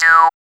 4504R SYNTON.wav